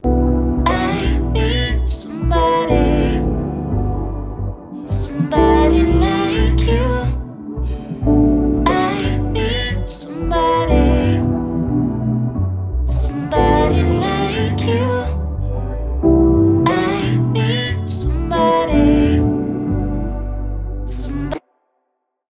That’s why our Pitch Shifter is powered by a cutting-edge, industry-leading algorithm designed to ensure high-quality, natural-sounding pitch shifts.
After Pitch Shifter
with-pitchshift.mp3